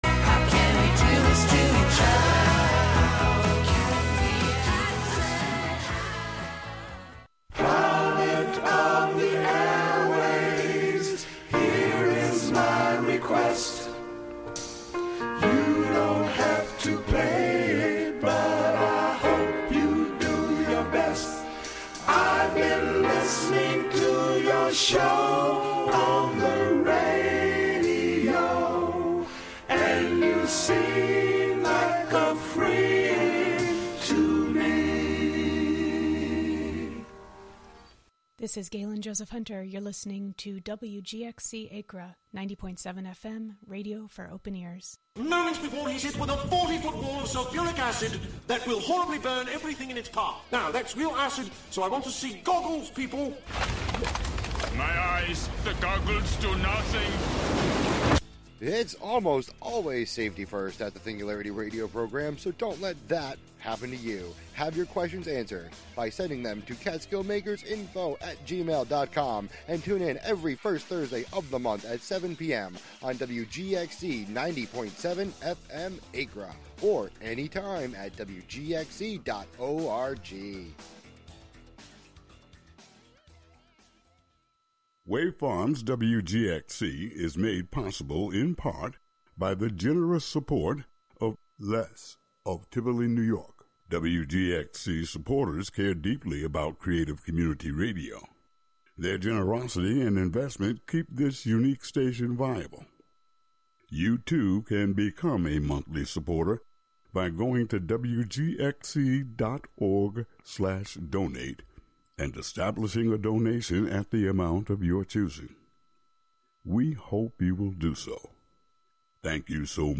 a patchwork of song, sound, and story that sifts through changing moods and places. expect the sound of crickets, manifestos, current song obsessions, overheard poems, dollar bin tapes, and the like, with a lingering emphasis on underground musicians that defy genre and expectation.